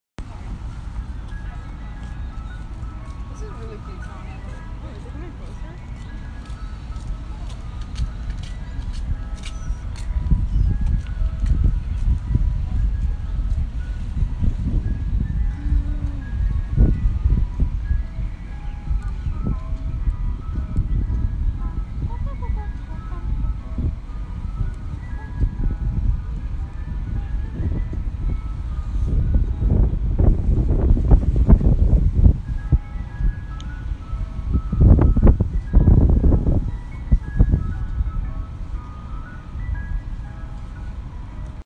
Field Recording
ice cream truck